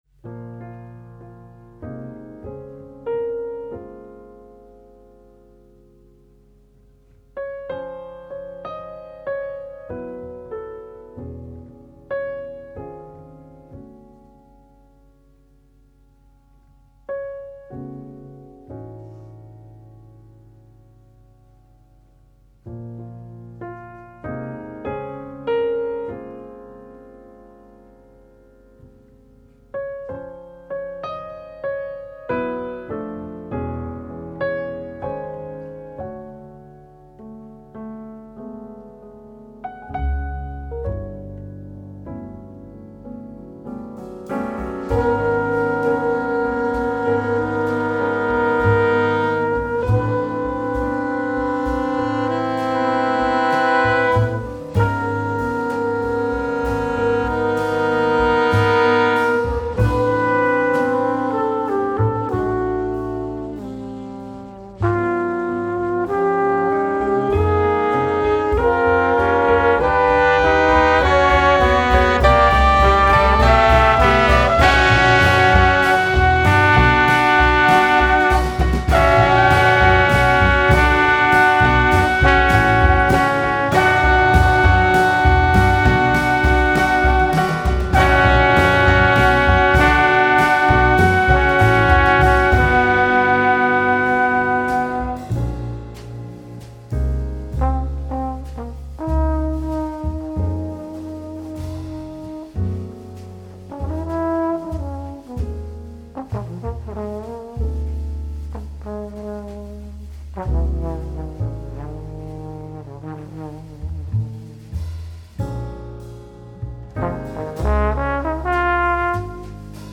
contemporary jazz
trumpet
alto saxophone
tenor saxophone
double bass
drums